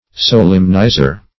Solemnizer \Sol"em*ni`zer\, n. One who solemnizes.